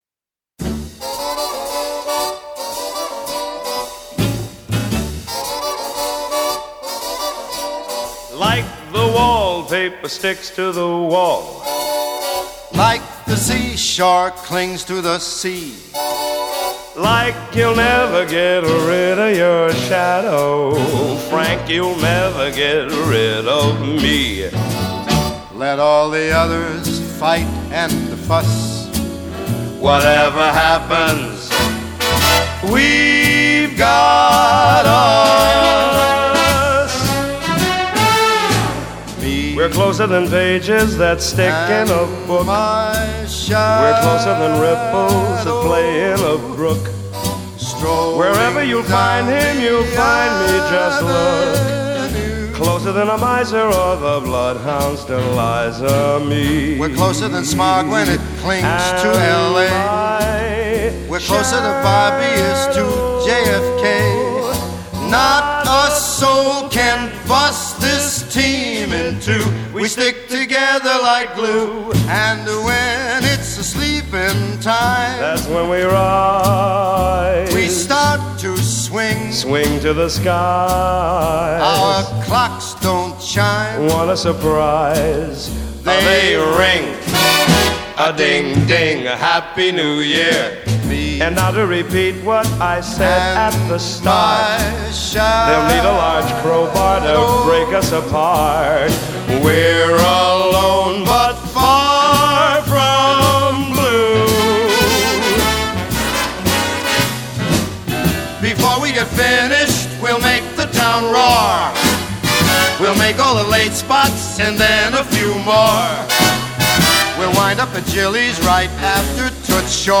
Jazz, Pop, Swing